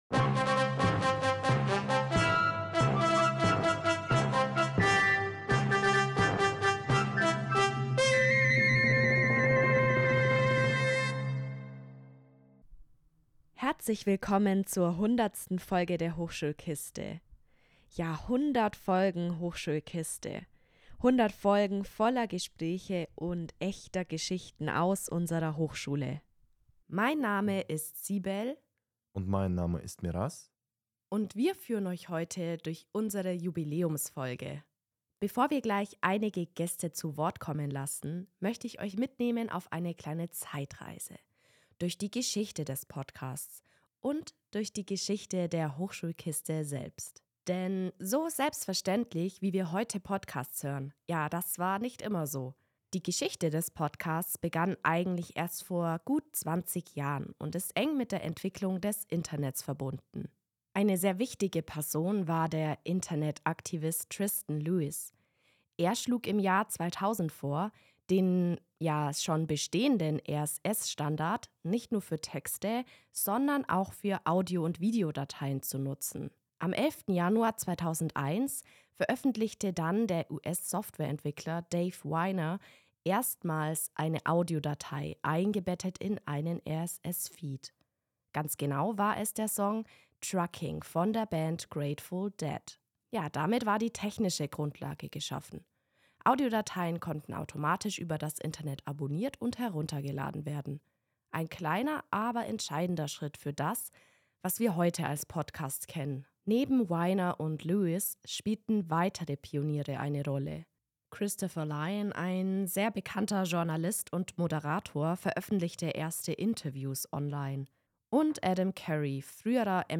Beschreibung vor 5 Monaten Wir feiern 100 Folgen Hochschulkiste! Wir starten mit einem Rückblick auf die Geschichte des Podcastings und erzählen, wie die Hochschulkiste als studentisches Projekt entstanden ist. Anschließend folgt unser Jubiläumsformat „100 Sekunden zur 100. Folge", in dem Lehrende und Mitarbeitende aus der Hochschule ihre Gedanken, Wünsche und Erinnerungen teilen.
Zum Abschluss hören wir persönliche Grüße und Glückwünsche von Studierenden.